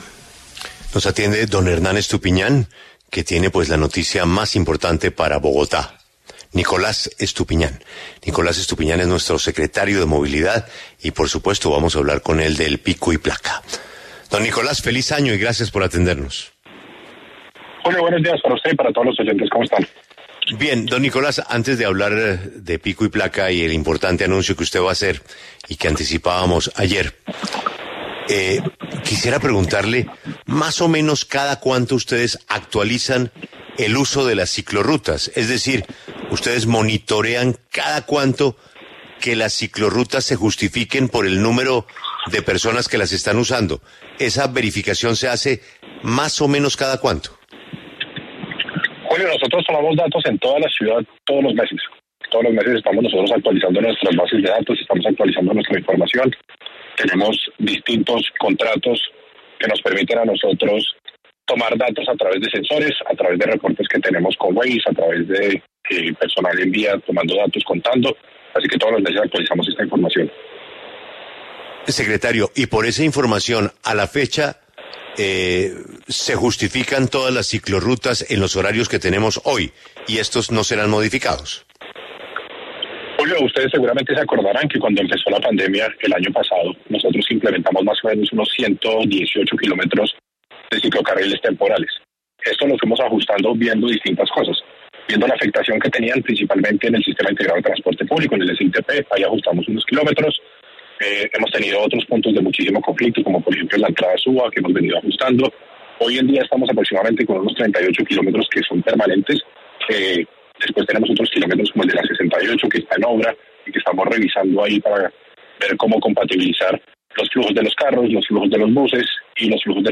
Nicolás Estupiñán, secretario de Movilidad de Bogotá, se pronunció en La W sobre el uso de ciclorrutas en Bogotá y los cambios al pico y placa que aplicarán desde enero de 2022.